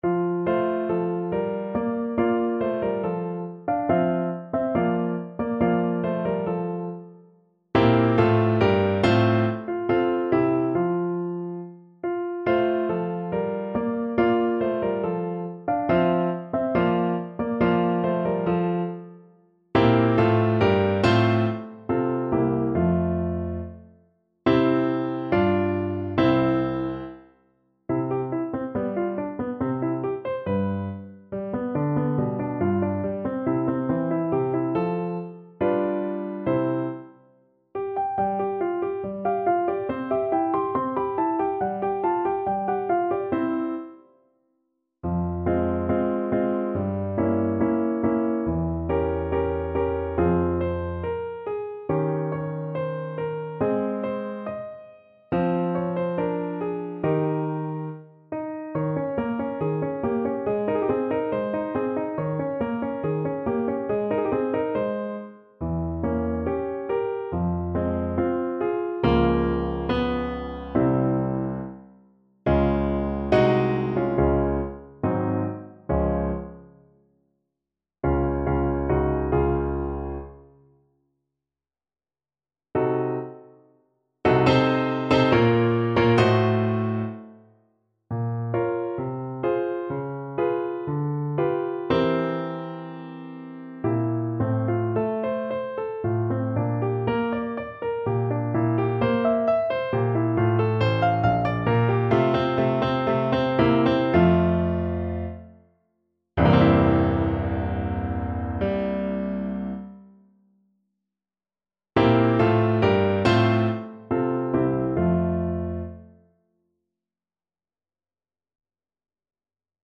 = 70 Allegretto
2/4 (View more 2/4 Music)
Classical (View more Classical Trumpet Music)